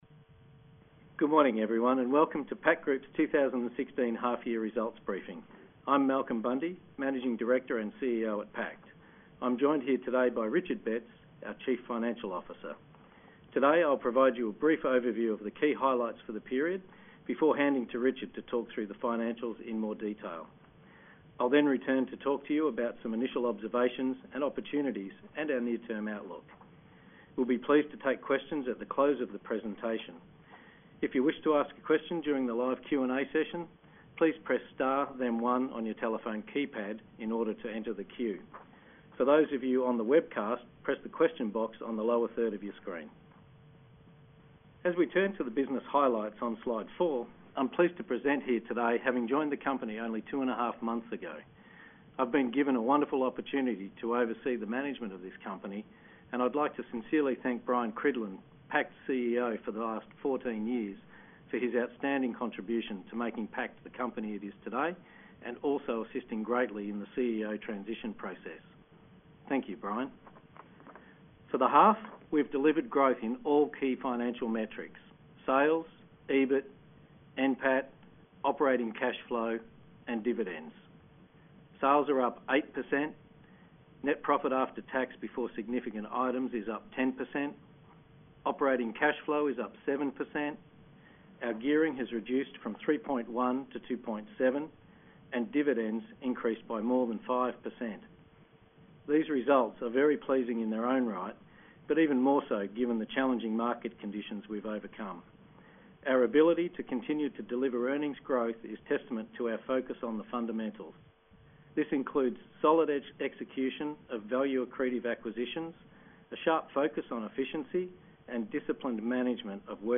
24 February 2016 Audio – Half Year Results Presentation and Q&A View 24 February 2016 Half Year Results Presentation View 24 February 2016 Media Release View 24 February 2016 Half Yearly Results & Accounts View